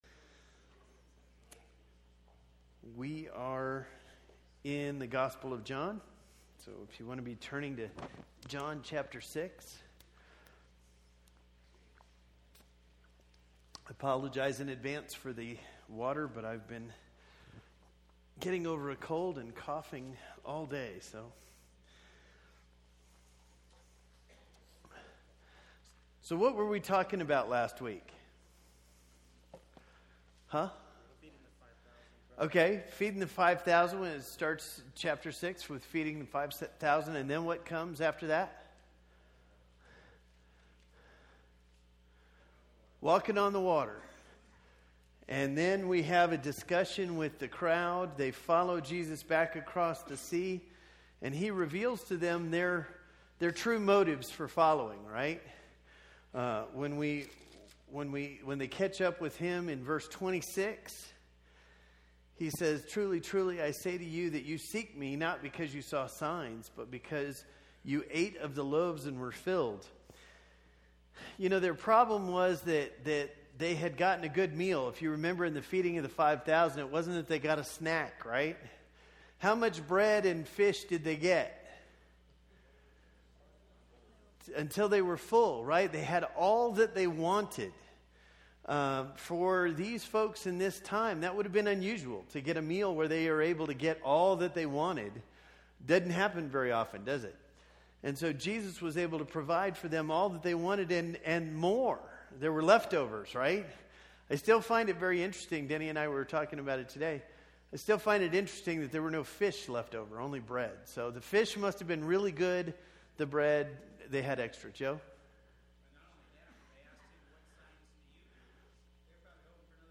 This is a study of the I AM statements of Jesus in the Gospel of John. Tonight's lesson is Part 2 and comes from John 6. These presentations are part of the Wednesday night adult Bible classes at the Bear Valley church of Christ.